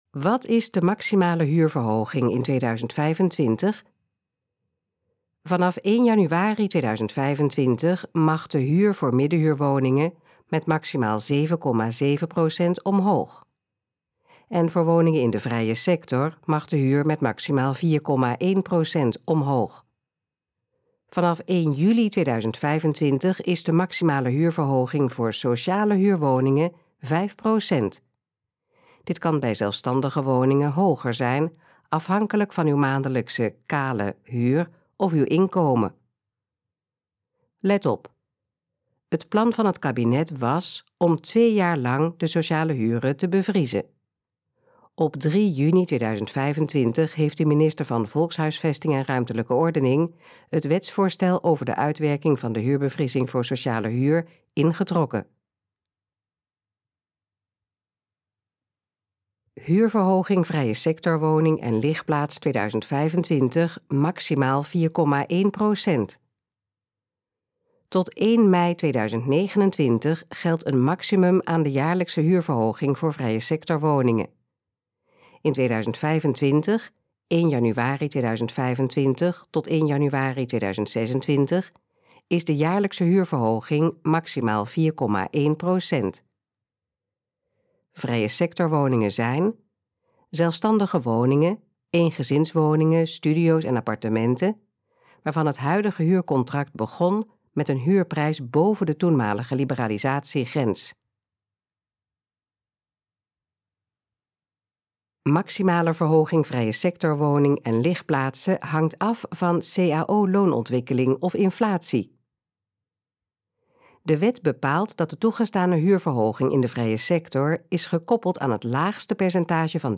Gesproken versie van: Wat is de maximale huurverhoging in 2025?
Dit geluidsfragment is de gesproken versie van de pagina: Wat is de maximale huurverhoging in 2025?